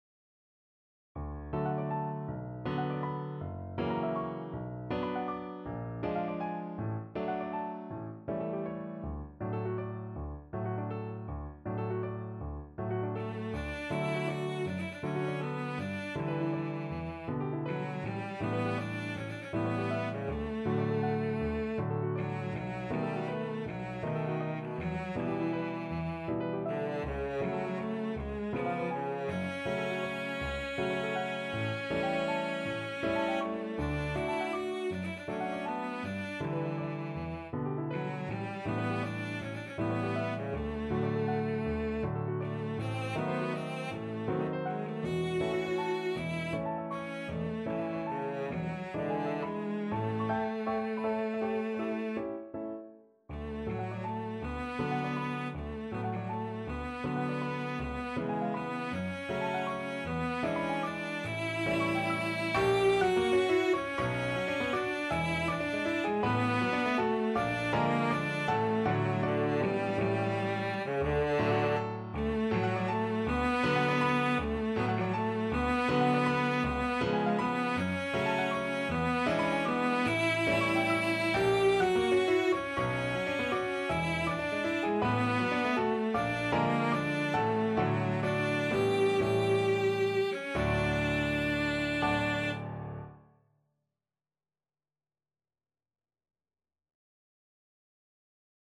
Classical Leoncavallo, Ruggero Mattinata Cello version
D major (Sounding Pitch) (View more D major Music for Cello )
6/8 (View more 6/8 Music)
Classical (View more Classical Cello Music)
mattinata_VLC.mp3